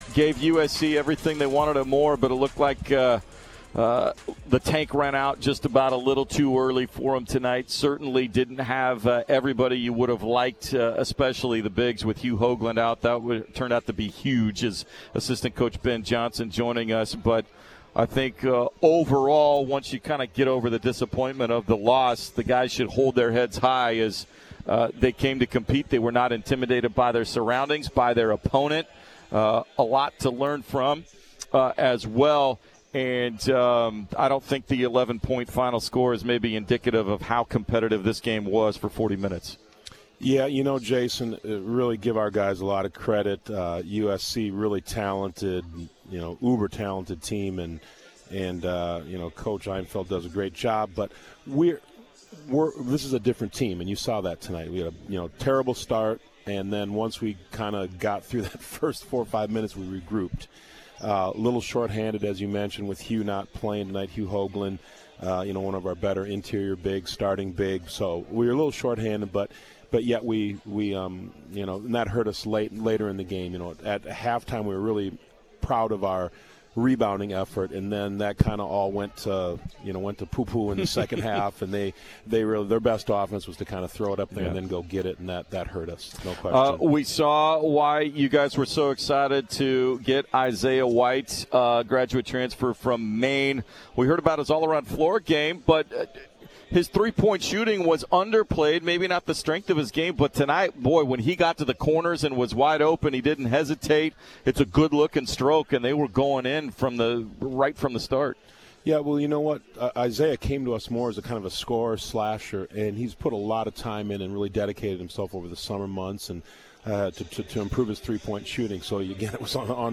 Men's Hoops Post-Game Interview at USC